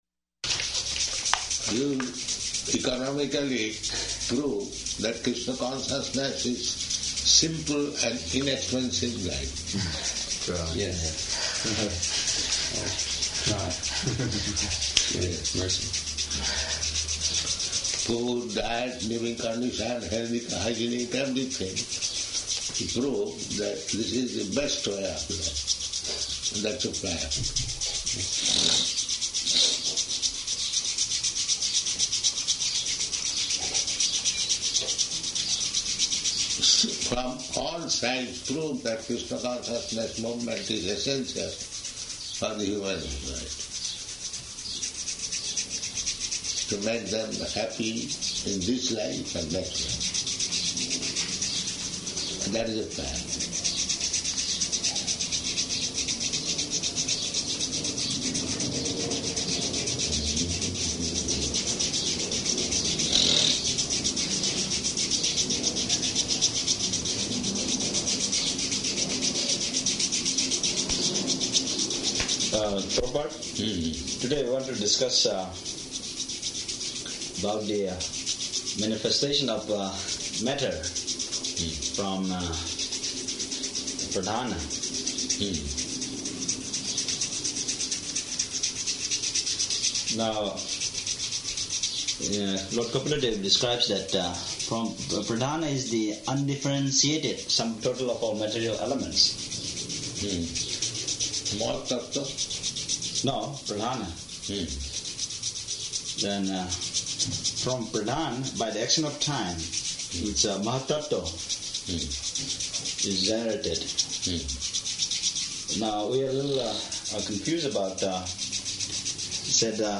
Room Conversation
Room Conversation --:-- --:-- Type: Conversation Dated: July 6th 1976 Location: Washington, D.C. Audio file: 760706R1.WDC.mp3 Prabhupāda: You economically prove that Kṛṣṇa consciousness is simple and inexpensive life.